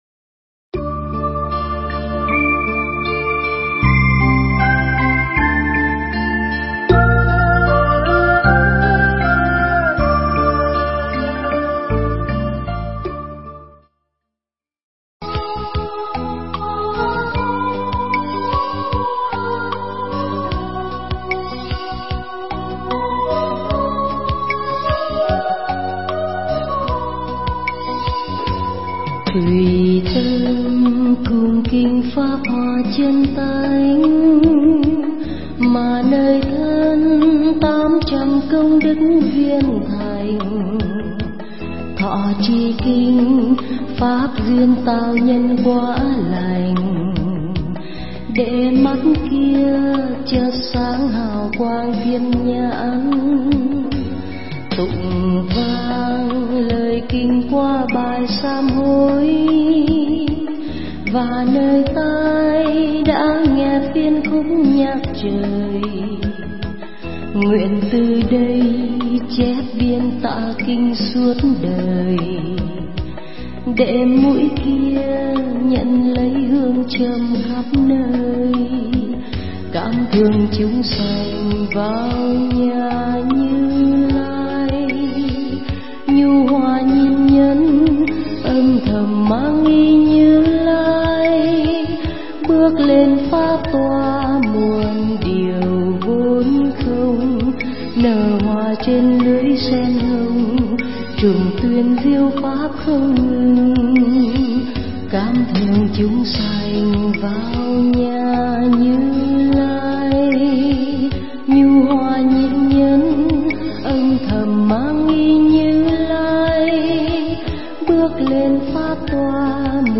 Bài Giảng mp3 Pháp Thoại Duyên Xưa – Hòa Thượng Thích Trí Quảng giảng tại Ấn Quang Tự, Quận 10, (ngày 6 tháng 5 năm Đinh Hợi), ngày 20 tháng 6 năm 2007